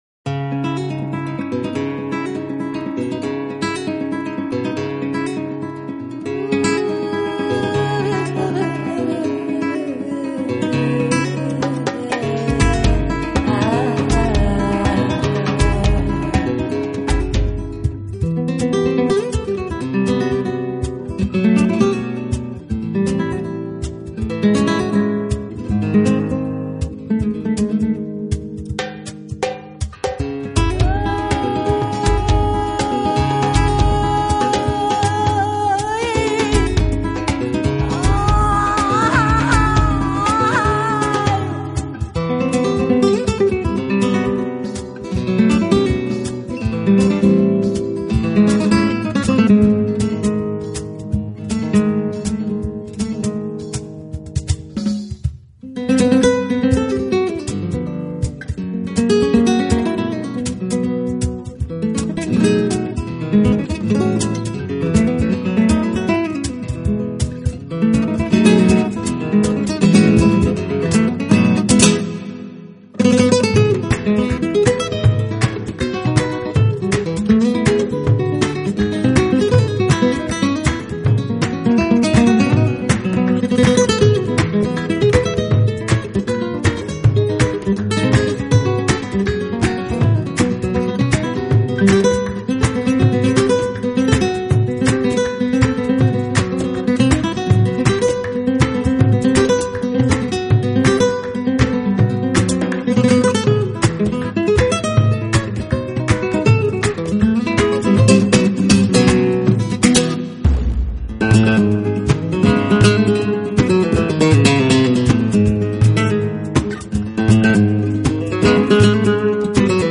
【吉他专辑】
的演奏相对一些Newage领域里的新佛莱明戈吉他手而言，显得
粗犷许多，带有强烈的个性色彩，同时与古典佛莱明戈比较，也显得更为流畅